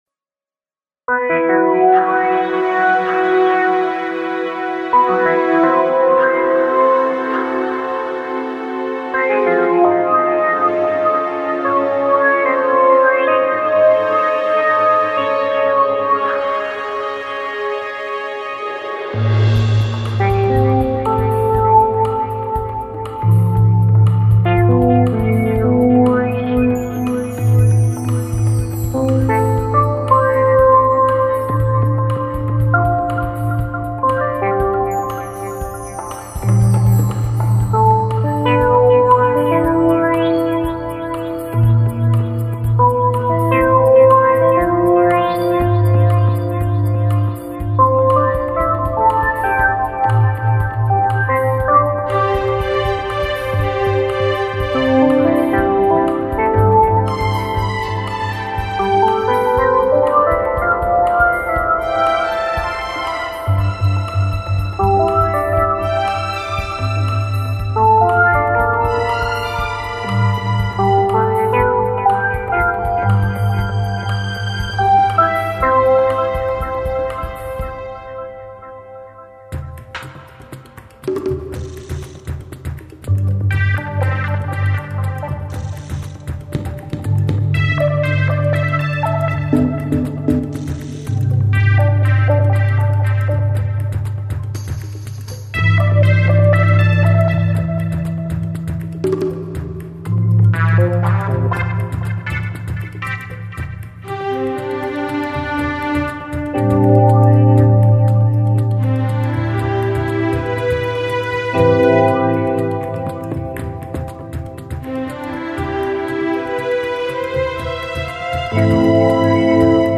Optimistic song
EASY LISTENING MUSIC